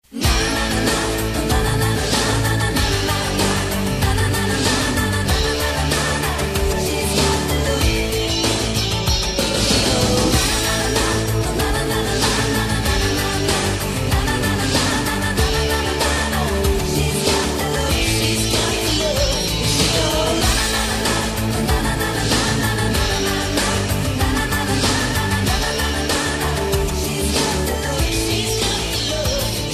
• Качество: 128, Stereo
мужской вокал
женский вокал
электрогитара
рок